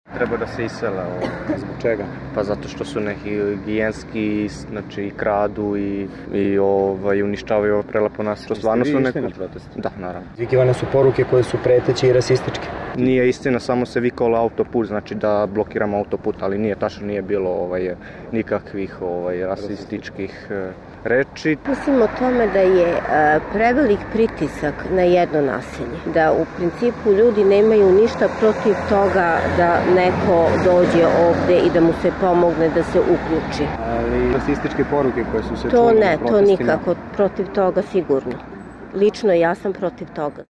Stotinak metara dalje, razgovarali smo sa meštanima Zemun Polja, od kojih su neki učestvovali i u protestima. Razlog je, tvrde, to što, citiramo, “Romi prave probleme u naselju”.
Građani